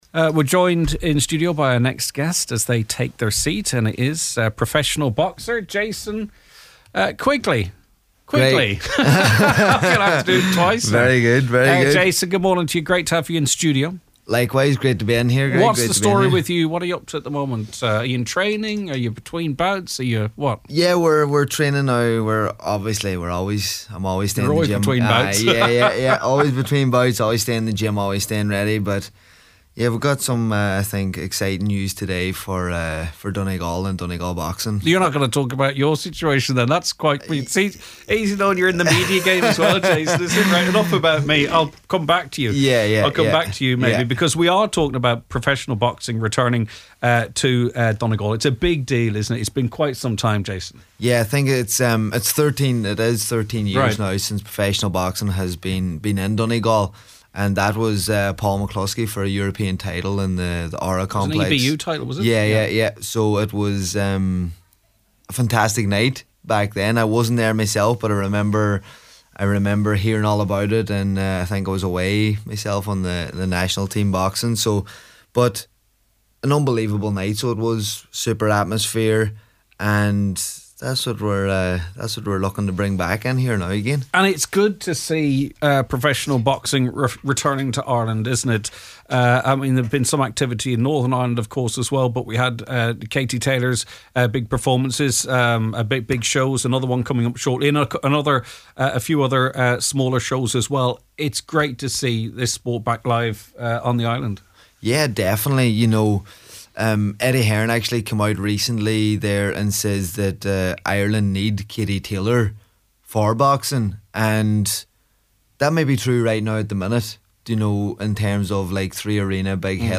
jason-qug-interview-2.mp3